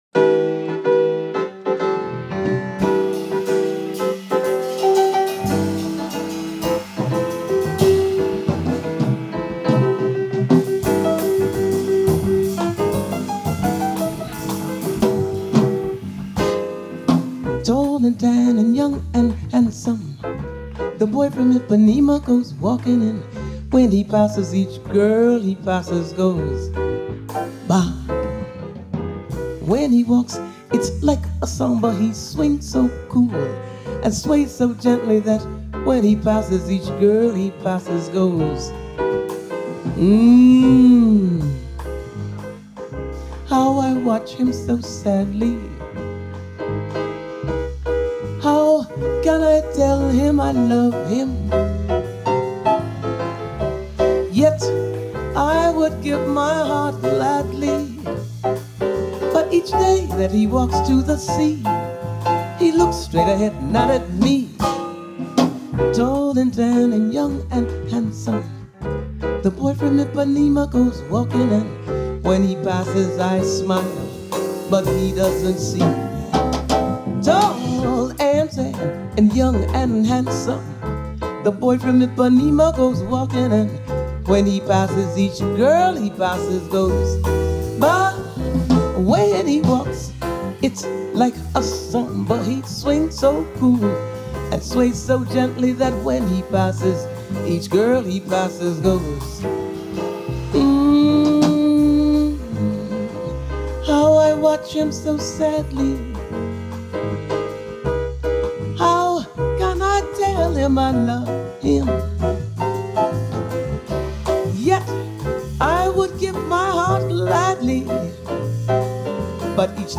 Жанр: Jazz Vocals.